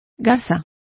Complete with pronunciation of the translation of herons.